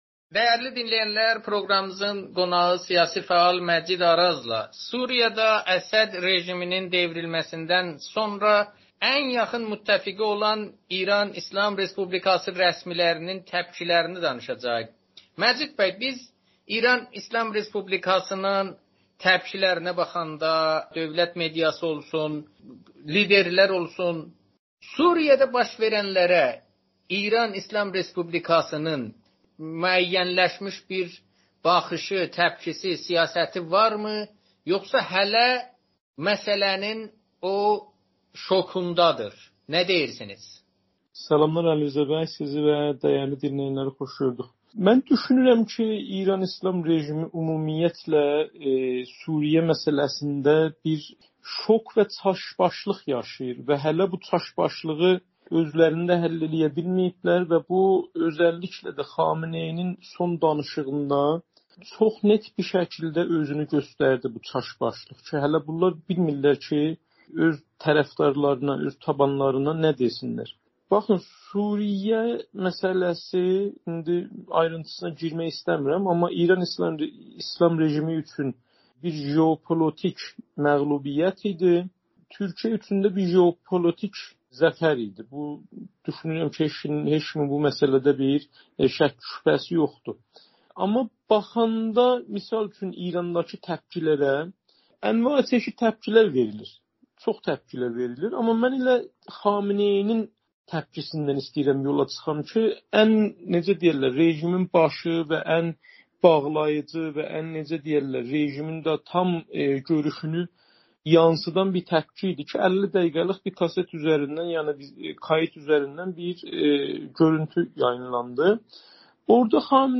Amerikanın Səsi ilə söhbətində